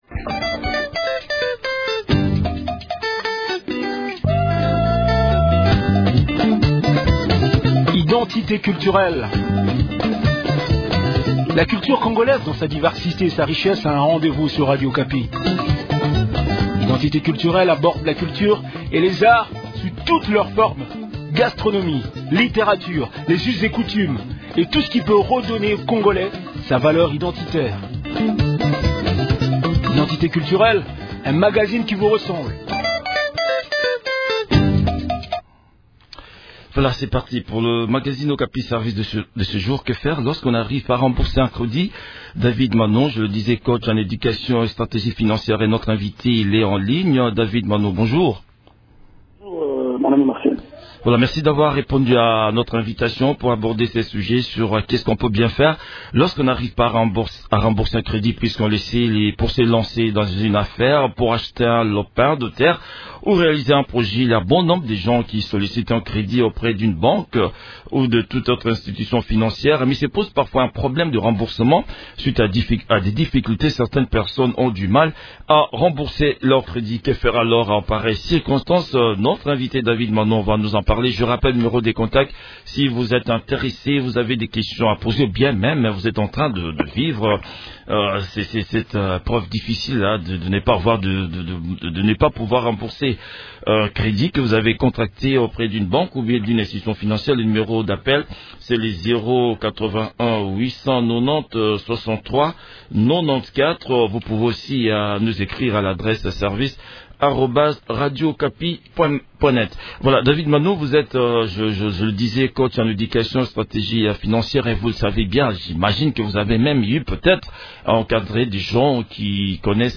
coach en éducation financière.